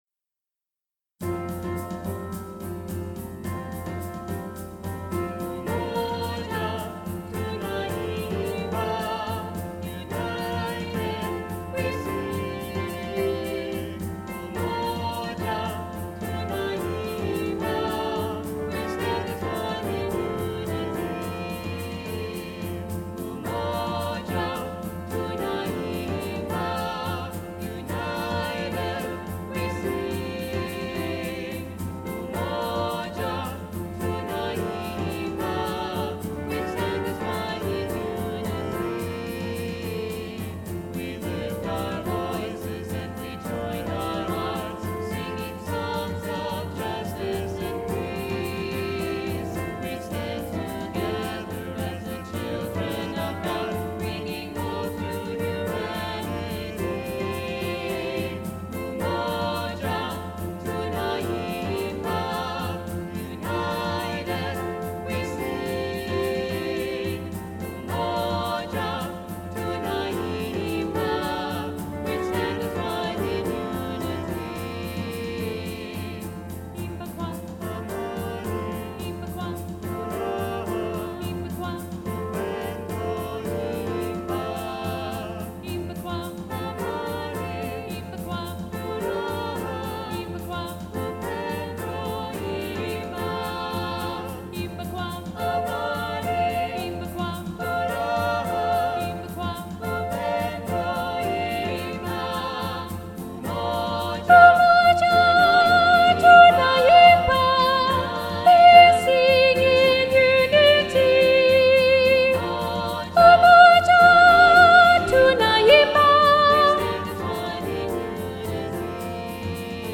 3-Part Mixed – Descant Predominant